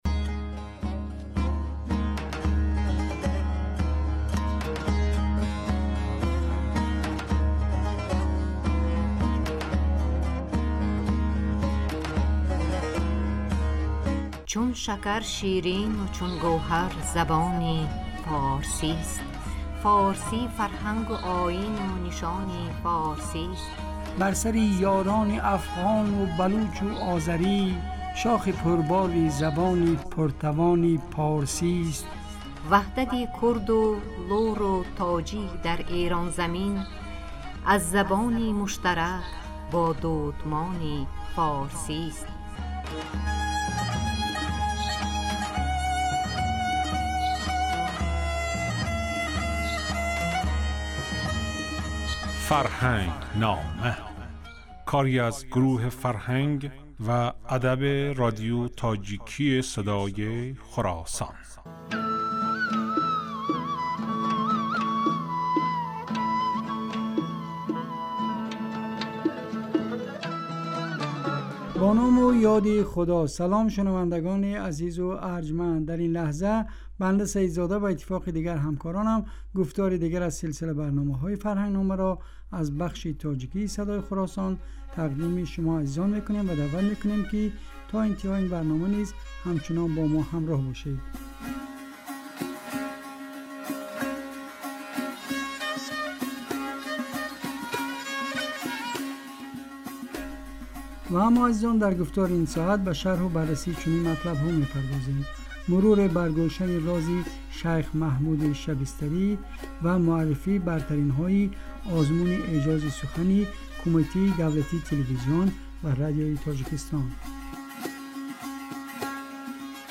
Ин гуфтор ҳар ҳафта рӯзи сешанбе, дар бахши субҳгоҳӣ ва шомгоҳӣ аз Садои Хуросон пахш мегардад.